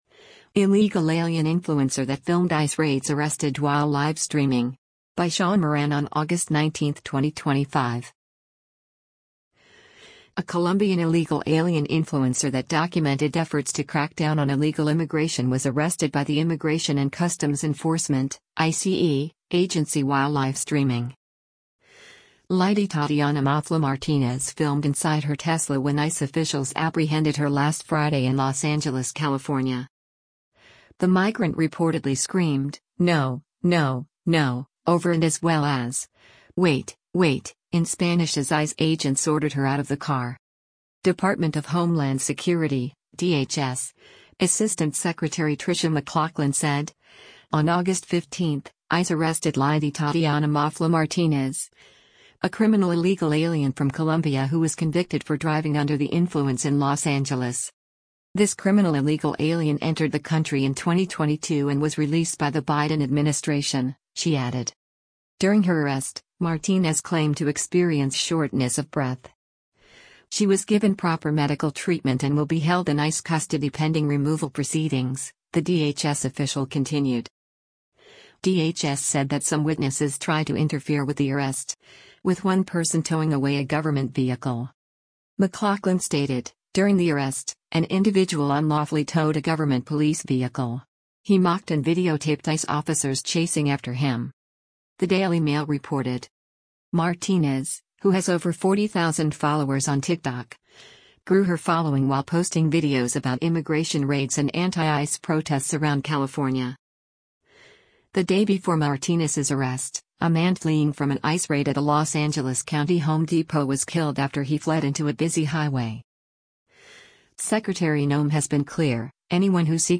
The migrant reportedly screamed, “no, no, no,” over and as well as, “wait, wait,” in Spanish as ICE agents ordered her out of the car.